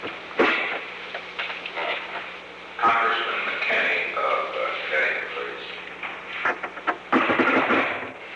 Recording Device: Oval Office
The Oval Office taping system captured this recording, which is known as Conversation 660-020 of the White House Tapes. Nixon Library Finding Aid: Conversation No. 660-20 Date: January 31, 1972 Time: Unknown between 10:59 am and 11:03 am Location: Oval Office The President talked with the White House operator. Request for a call to Stewart B. McKinney